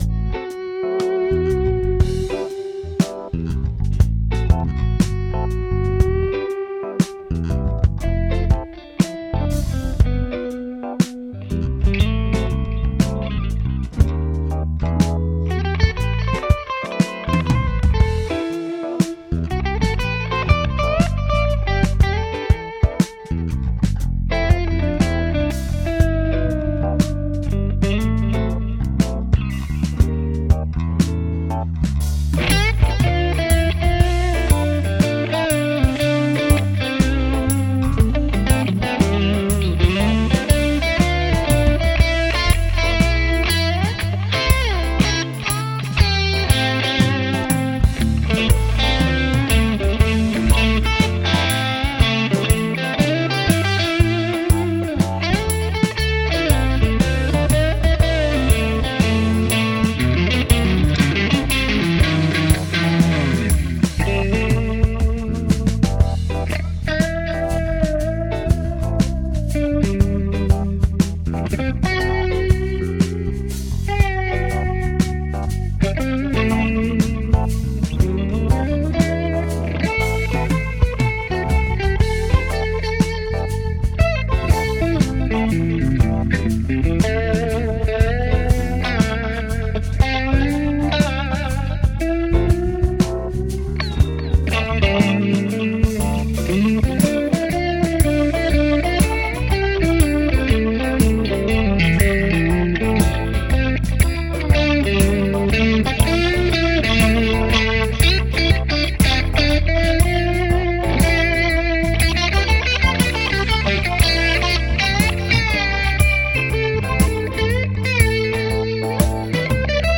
Hier sind Aufnahmen an denen ich mit meinem Bass beteiligt bin.
Gitarre